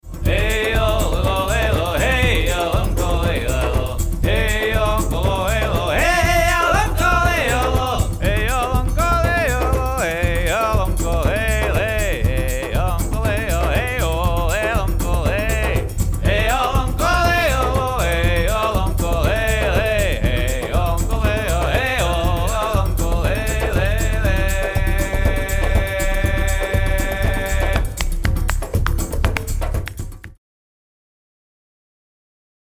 vocals, electric bass
percussion
Recorded in Joikeredet, Hammerfest